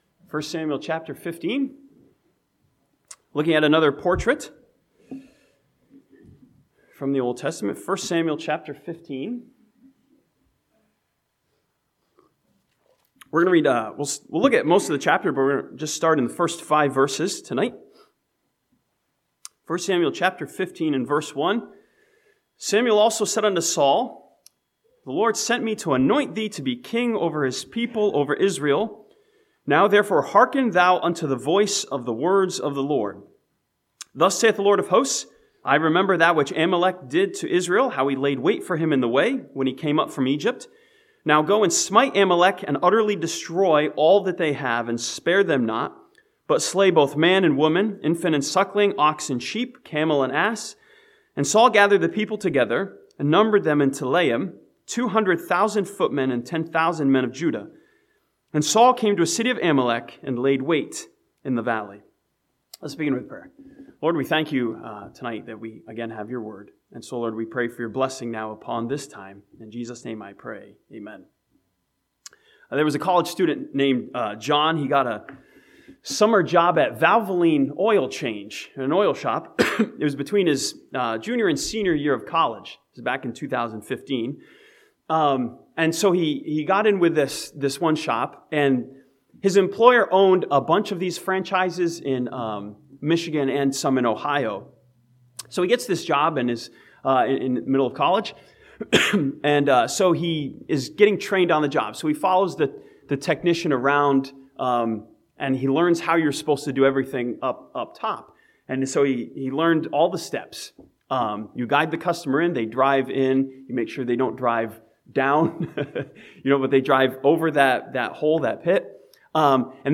This sermon from 1 Samuel chapter 15 studies Saul's disobedience as a portrait of incompletion in the eyes of God.